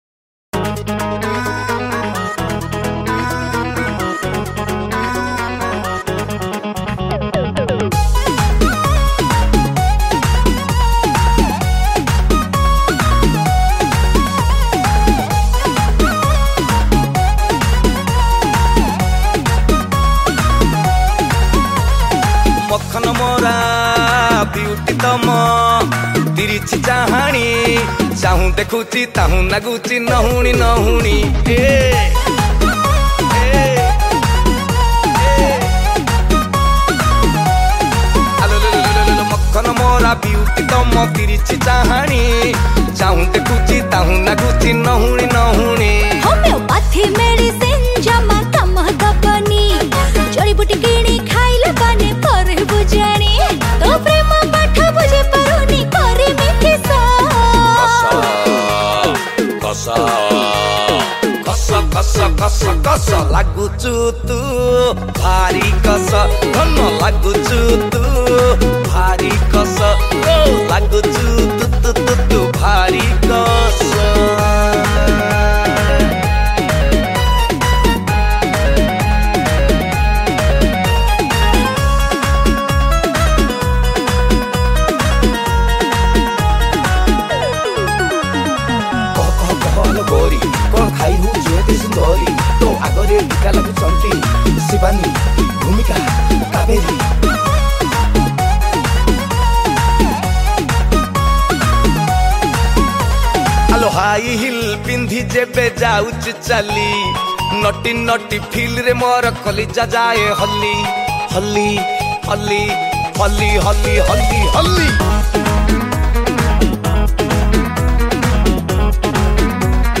Recorded At : New Smruti Studio, BBSR